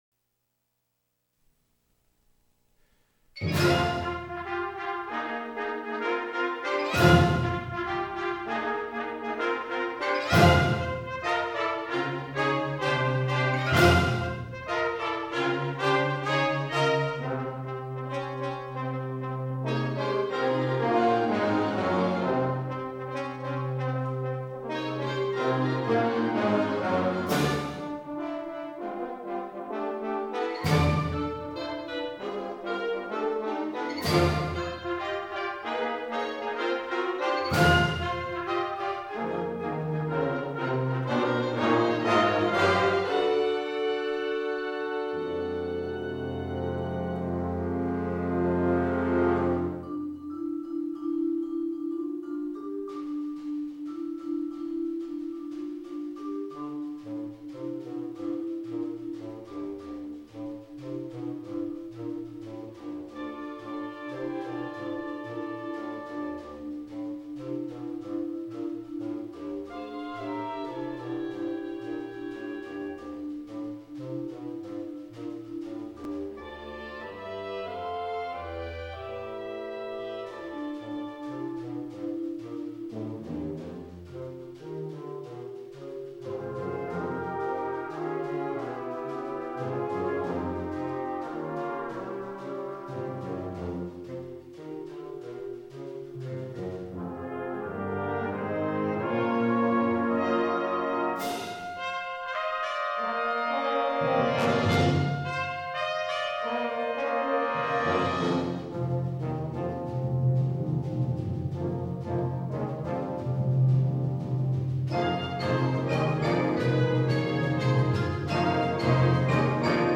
Genre: Band
The piece starts with a chase scene of sorts.